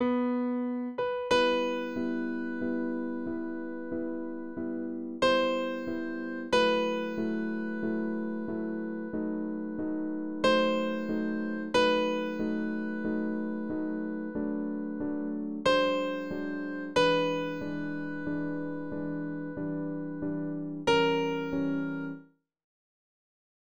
電子音を明確に鳴らすために著者がやむをえず付記したものです。